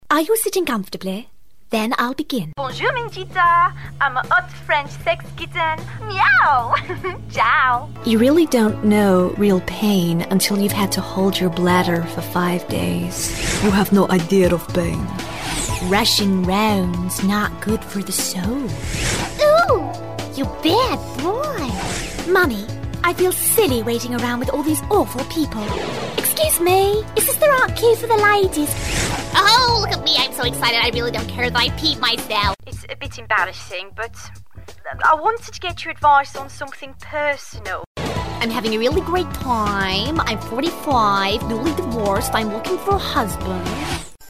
• Native Accent: London, RP
• Home Studio